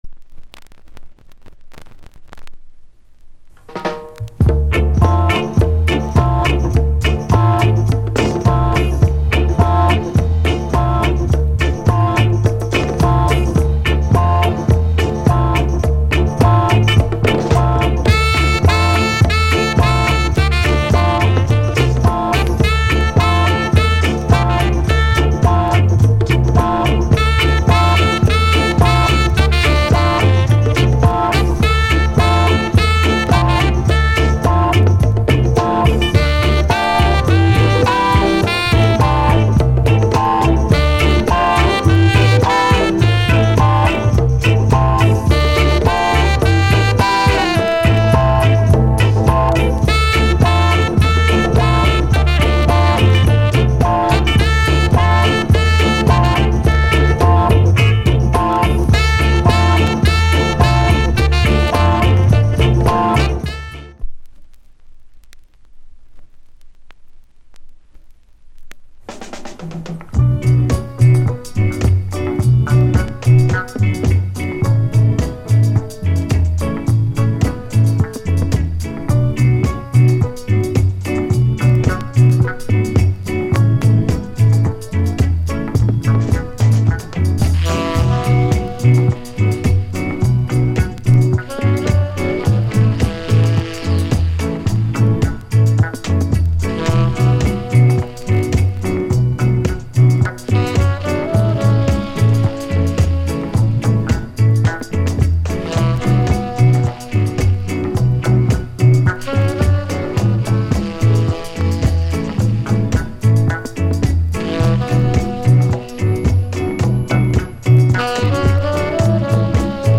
* Great Sax Inst** 試聴 A-2.